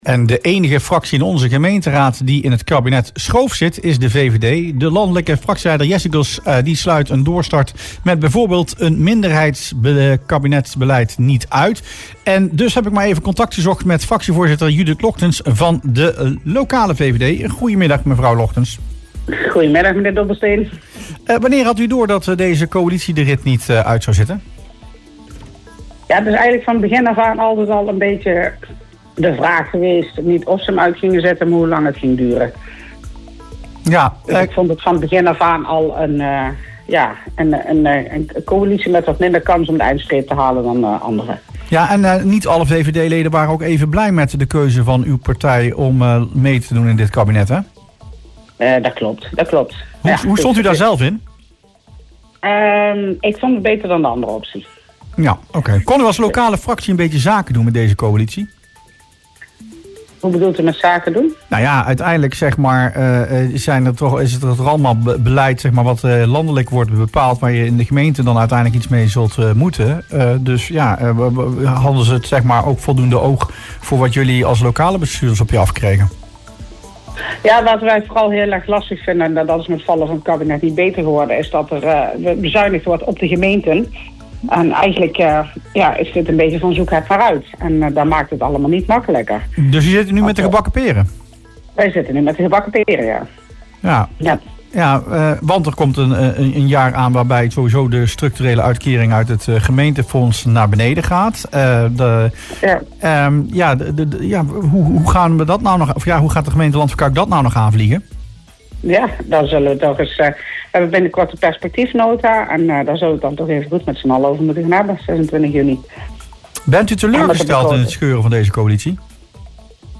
VVD-fractievoorzitter Judith Logtens in Rustplaats Lokkant